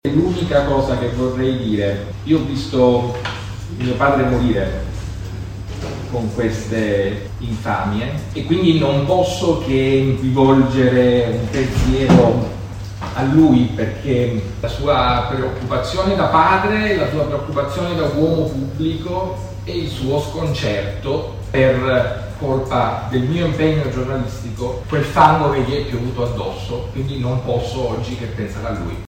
Il “disegno criminoso” ordito ai danni di Paolo Borrometi: le parole del giornalista in conferenza stampa
Questo è per la Fnsi ciò che è stato ordito ai danni del giornalista Paolo Borrometi vedendo coinvolti un politico, due giornalisti e una donna in accuse diffamatorie contro il presidente di Articolo 21. Ascoltiamo le sue parole ieri in conferenza stampa.